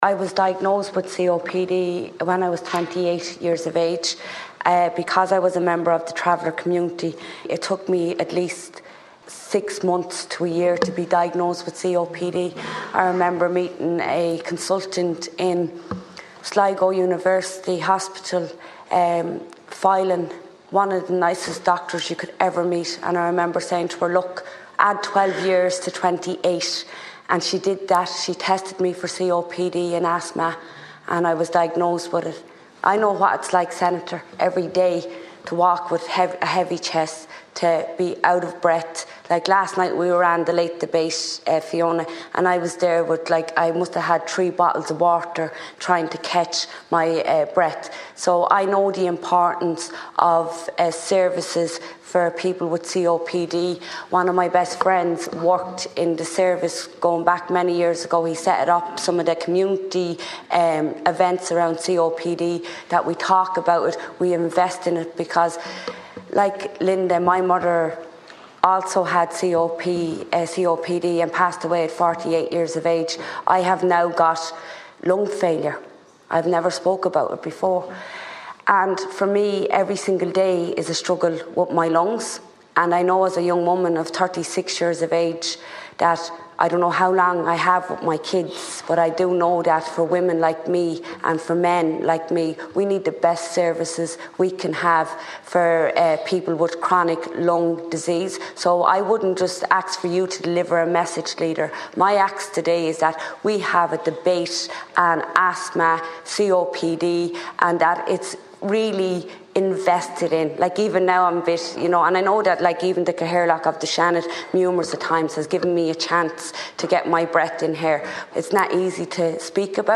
A Donegal senator who was diagnosed with COPD in her late twenties has made an impassioned plea in the Seanad for more investment in services for people with chronic lung problems.
Senator Eileen Flynn said she regularly has to pause to catch her breath while speaking in the chamber, and feels the pressure on her lungs on daily basis.